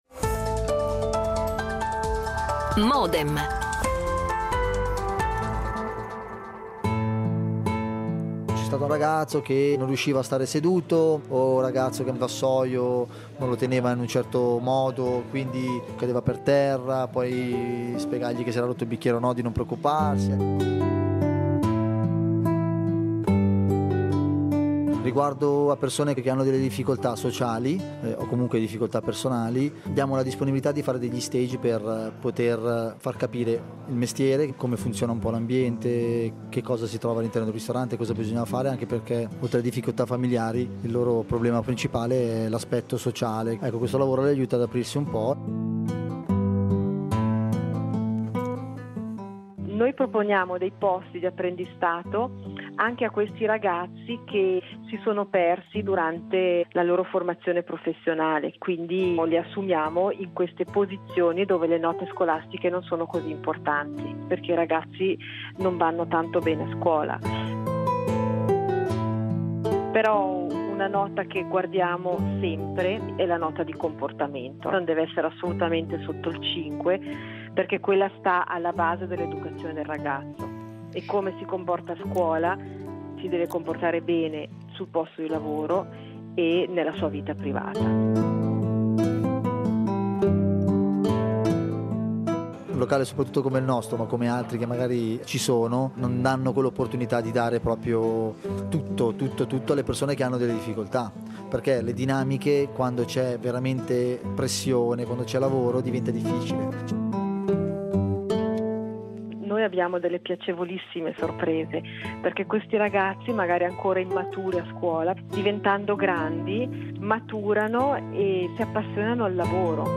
Con due testimonianze registrate
L'attualità approfondita, in diretta, tutte le mattine, da lunedì a venerdì